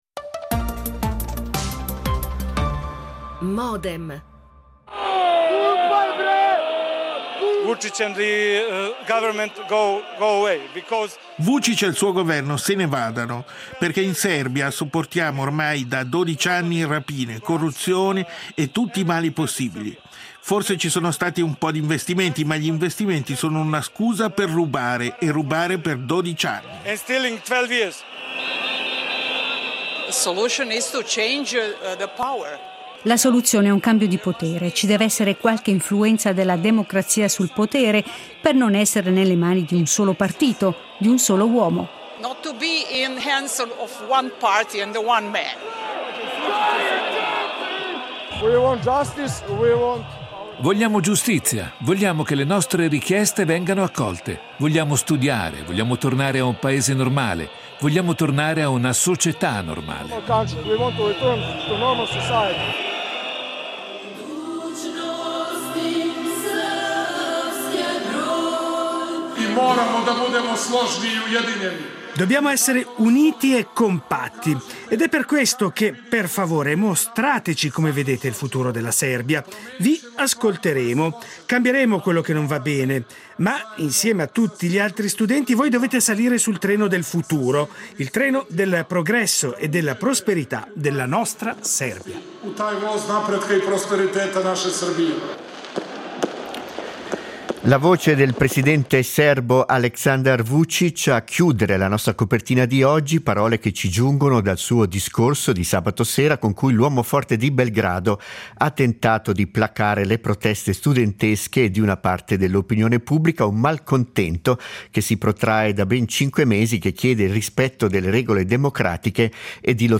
Modem, appuntamento quotidiano (dal lunedì al venerdì) in onda dal 2000, dedicato ai principali temi d’attualità, che vengono analizzati, approfonditi e contestualizzati principalmente attraverso l’apporto ed il confronto di ospiti in diretta.